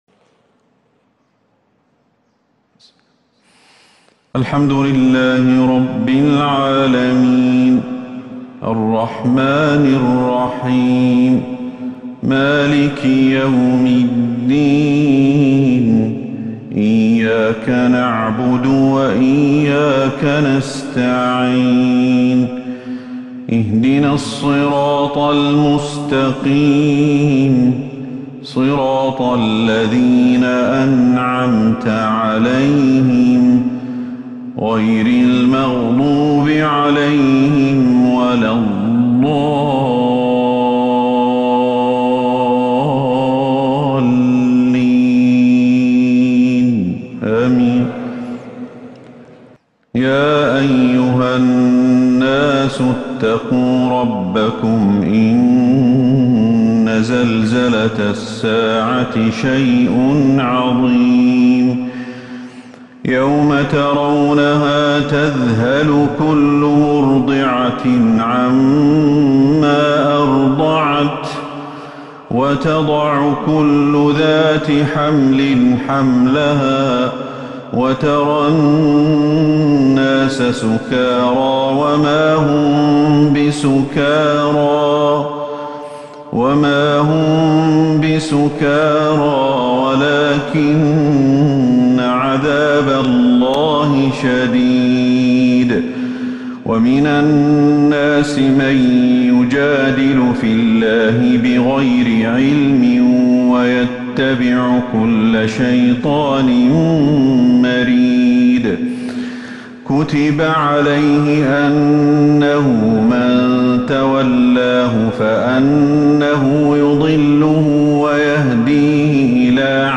فجر السبت 17 ربيع الأول 1443هـ فواتح سورة {الحج} > 1443 هـ > الفروض - تلاوات الشيخ أحمد الحذيفي